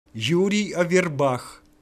PS How to pronounce Yuri Averbakh.
averbakh-pronunciation.mp3